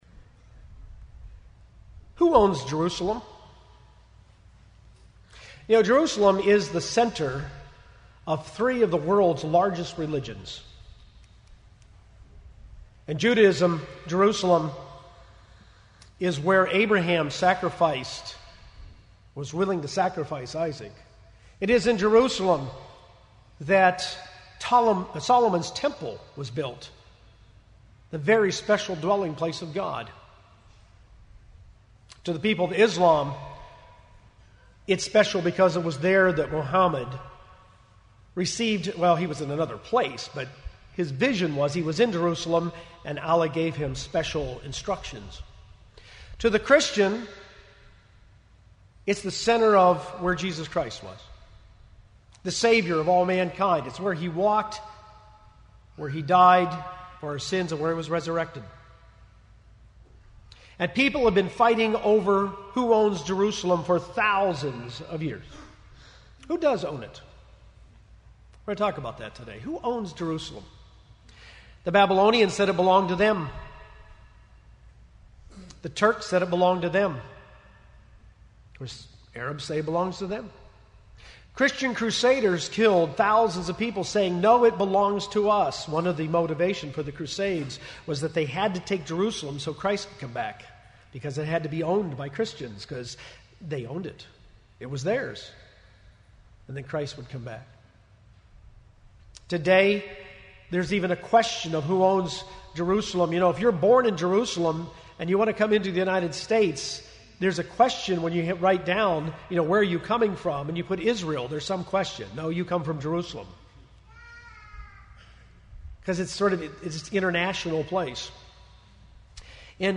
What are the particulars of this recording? This sermon was given at the Oceanside, California 2016 Feast site.